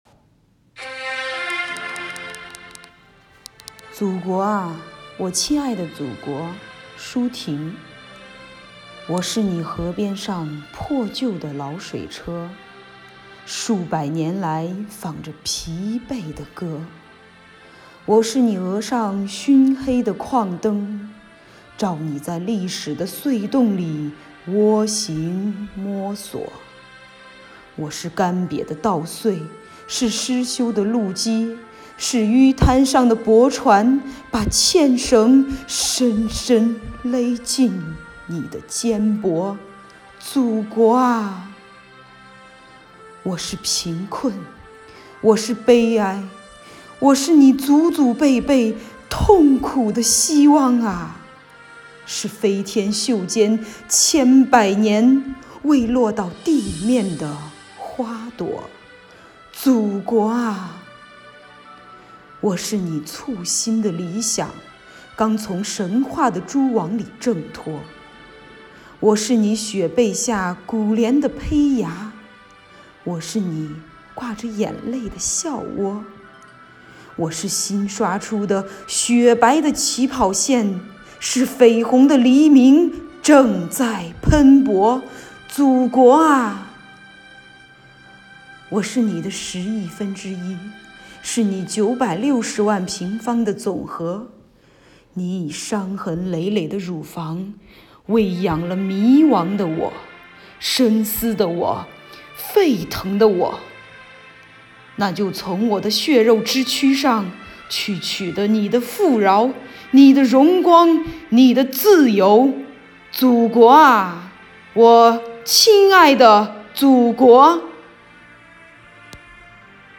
“悦读·新知·致敬”主题朗读比赛|优秀奖